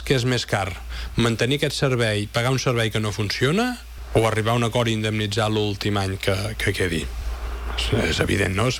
“Actualment el servei no està funcionant bé”, ha admès l’alcalde en declaracions a Ràdio Calella TV. Buch ha explicat que la decisió comportarà una indemnització a Urbaser, però ha defensat que “serà un mal menor”.